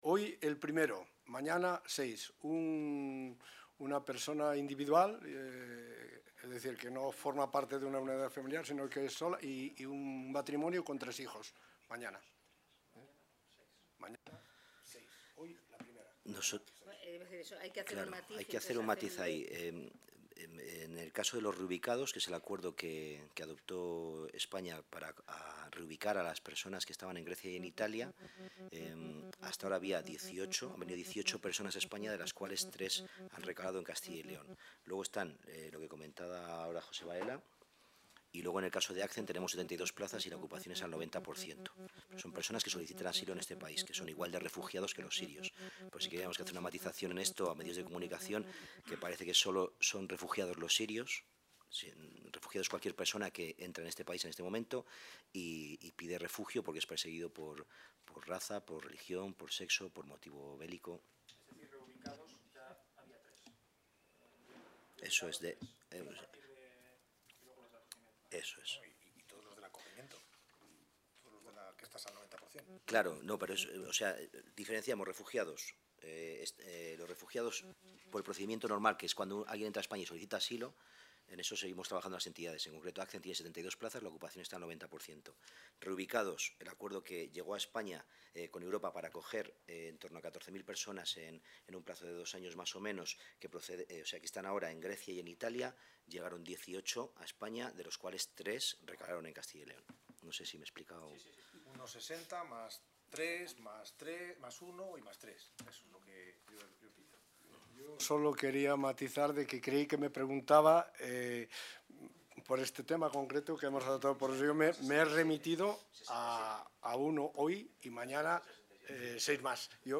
Rueda de prensa tras la reunión de la mesa de seguimiento para la acogida de personas refugiadas en Castilla y León.